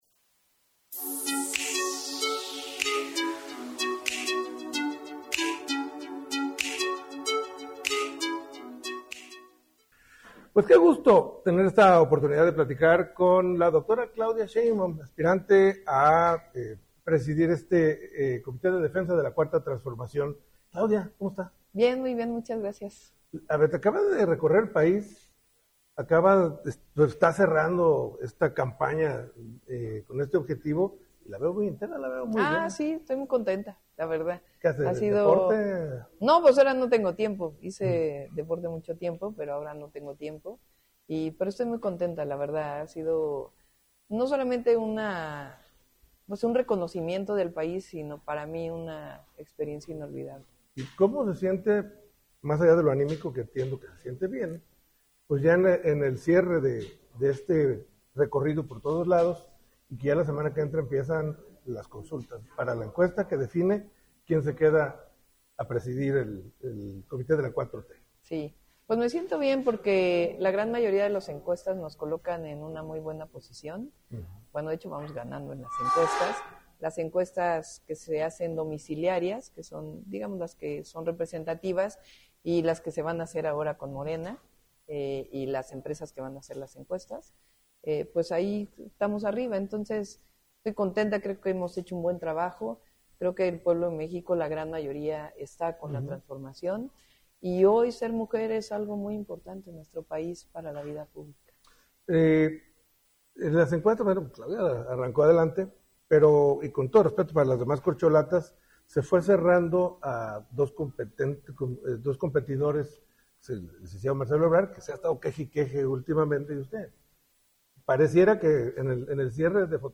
Claudia Sheinbaum en entrevista
ENTREVISTA-COMPLETA-CLAUDIA-.mp3